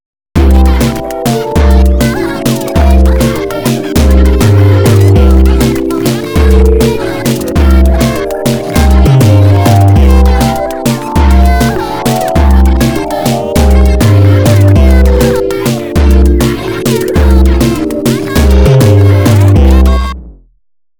Based of a default preset I made for UA Battalion…